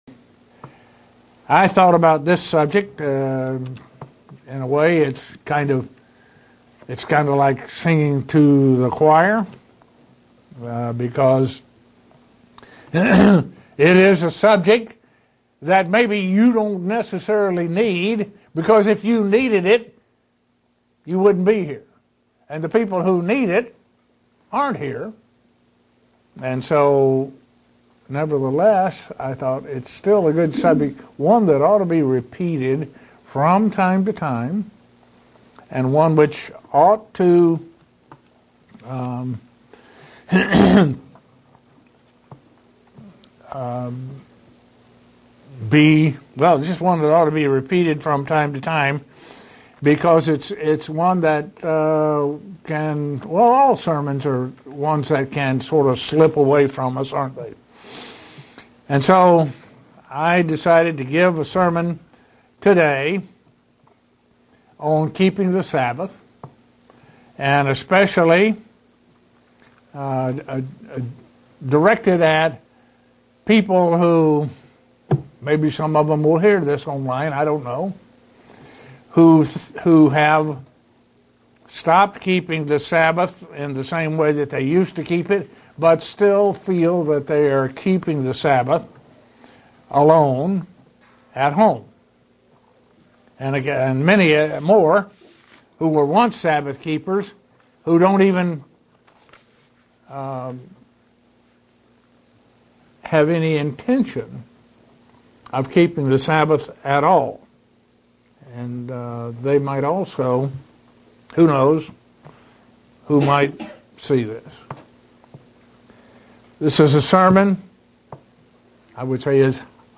Given in Buffalo, NY
Print Revisiting the Sabbath and some of the ways it should be kept. sermon Studying the bible?